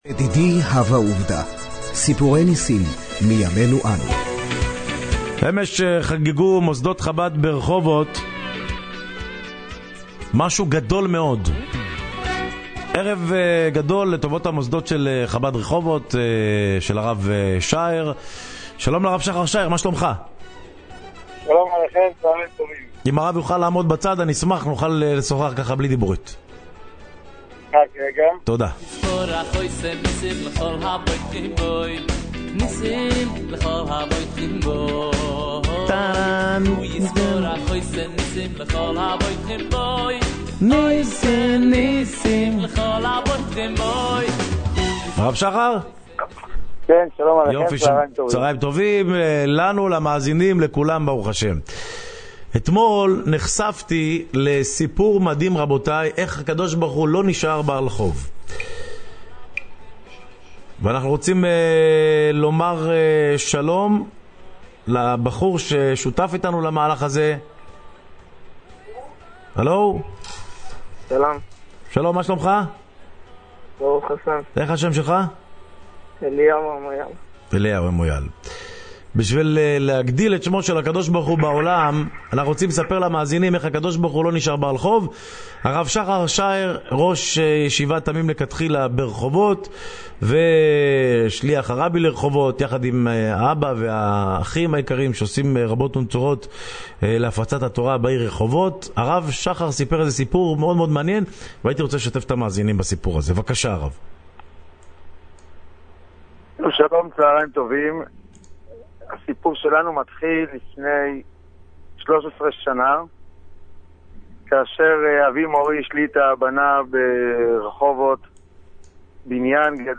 בשידור חי
שידור חוזר